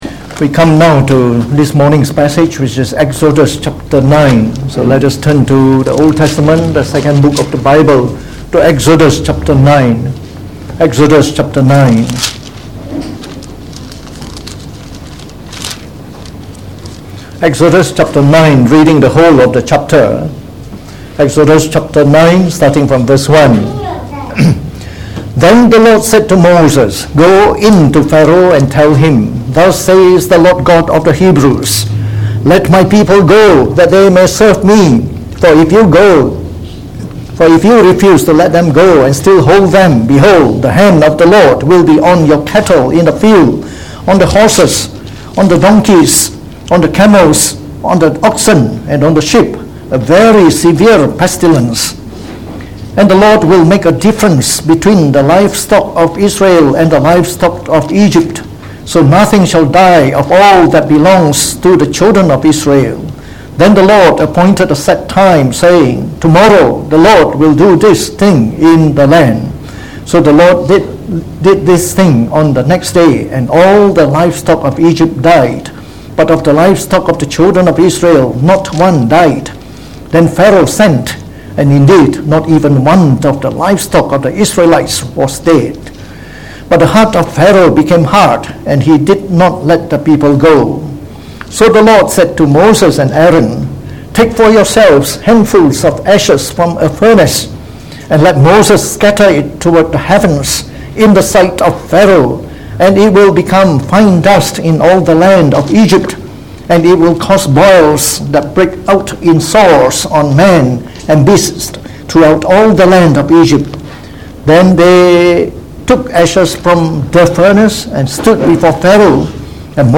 Preached on the 14th of July 2019.